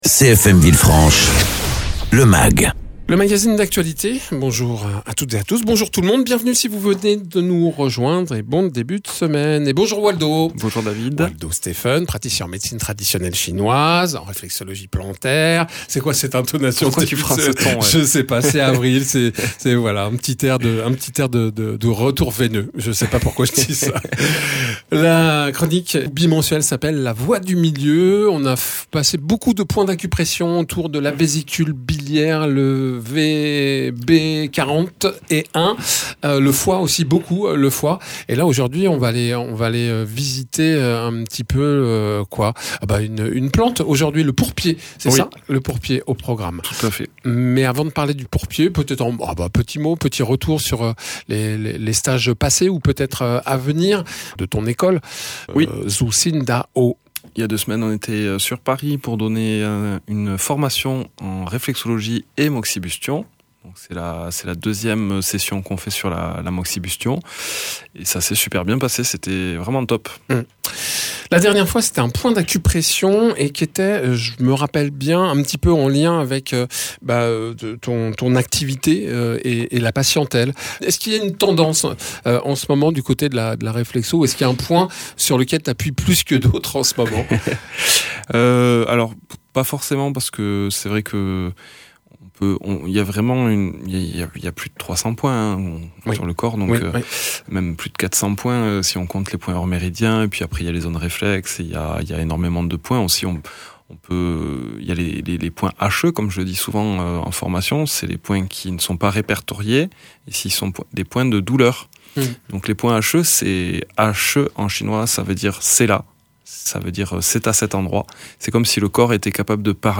praticien en réflexologie plantaire et Médecine Traditionnelle Chinoise.